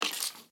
Minecraft Version Minecraft Version snapshot Latest Release | Latest Snapshot snapshot / assets / minecraft / sounds / mob / stray / step4.ogg Compare With Compare With Latest Release | Latest Snapshot
step4.ogg